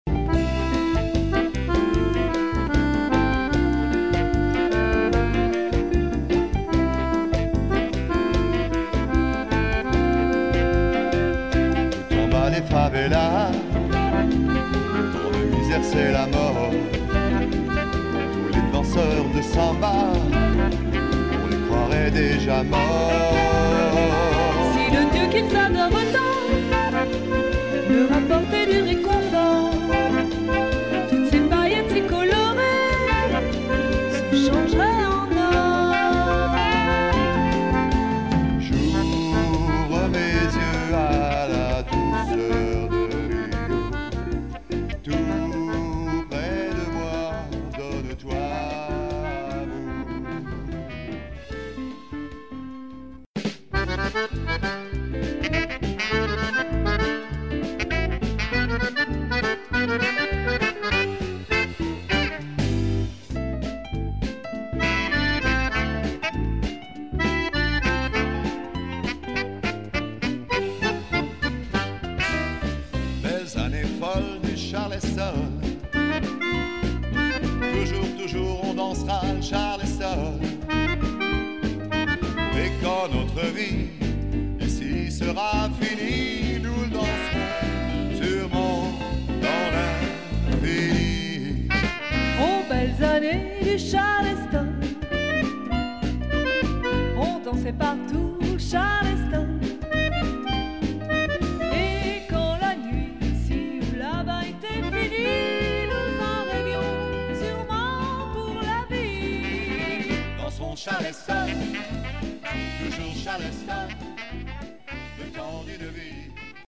(Bossa Nova)